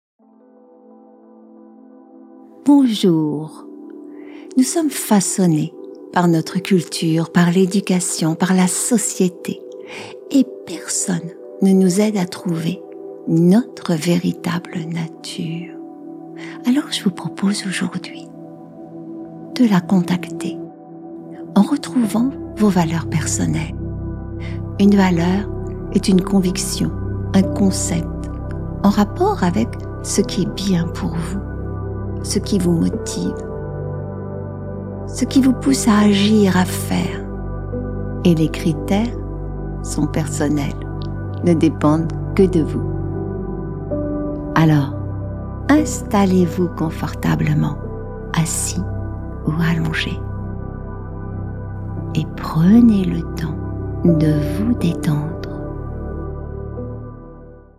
Méditation guidée – Estime de soi – les valeurs
Cette séance de méditation guidée peut se pratiquer aussi en journée.